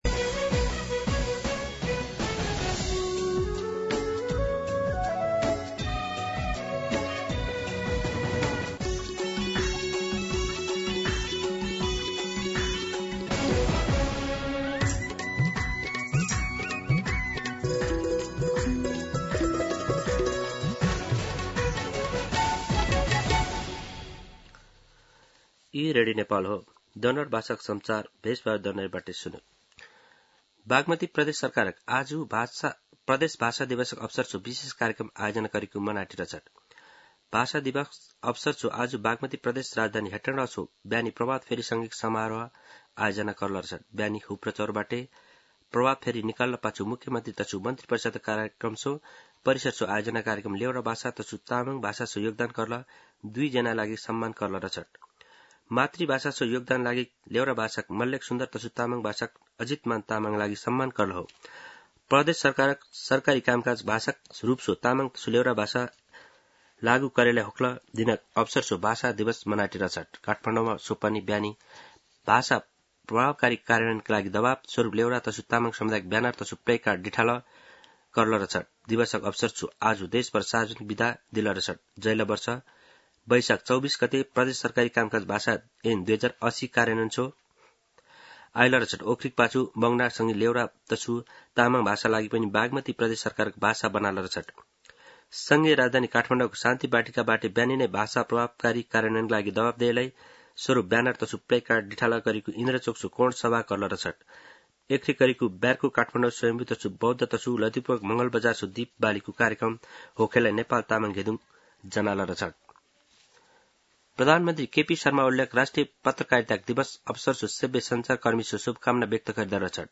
दनुवार भाषामा समाचार : २४ वैशाख , २०८२
Danuwar-News-01-24.mp3